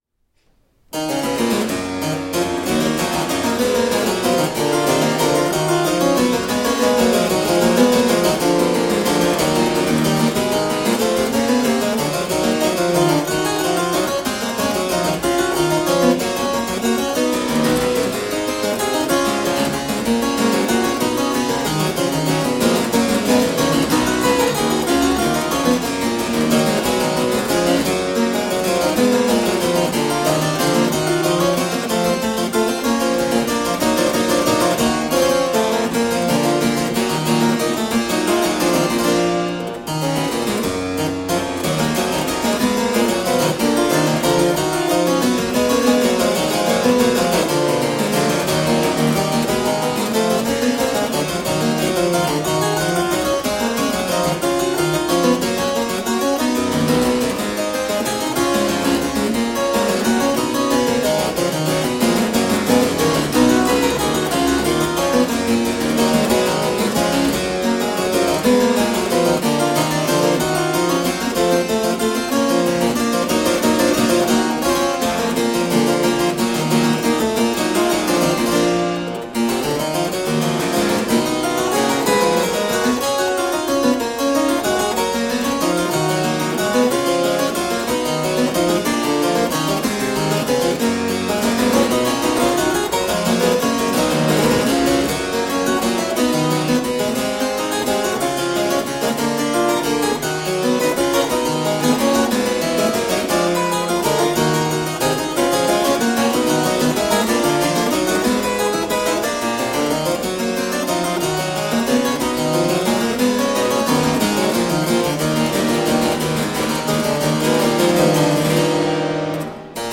Deeply elegant harpsichord.
Classical, Baroque, Renaissance, Instrumental
Harpsichord